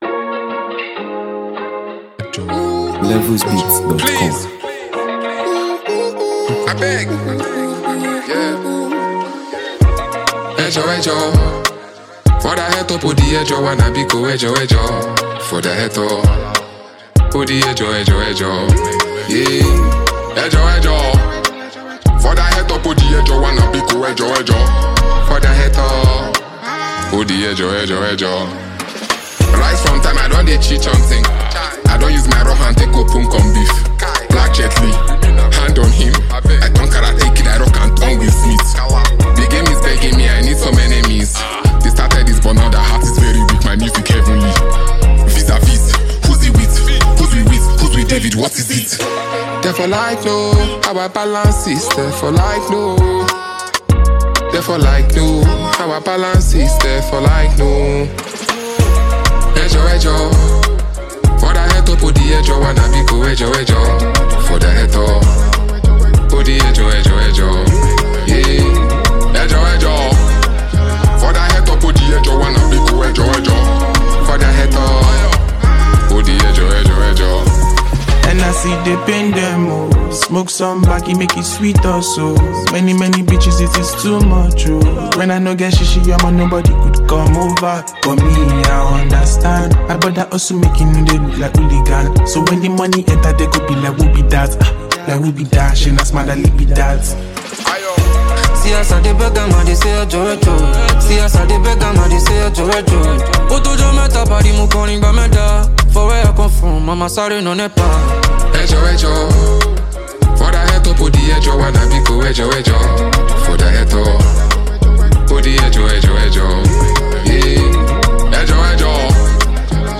Nigeria Music